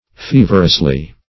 feverously - definition of feverously - synonyms, pronunciation, spelling from Free Dictionary Search Result for " feverously" : The Collaborative International Dictionary of English v.0.48: Feverously \Fe"ver*ous*ly\, adv.